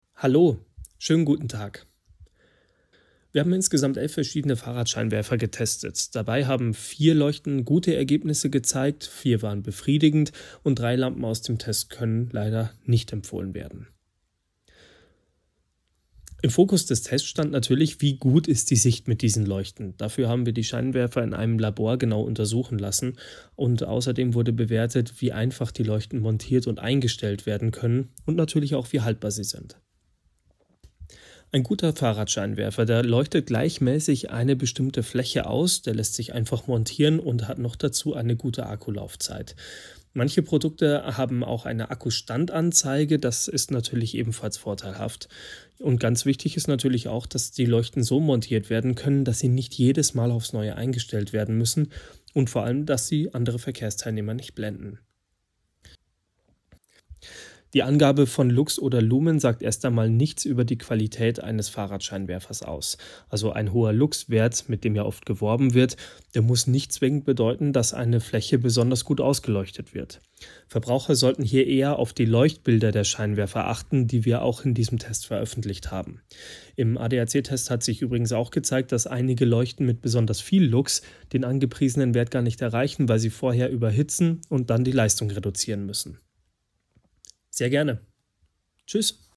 o-toene_adac_frontscheinwerfer-test_2025.mp3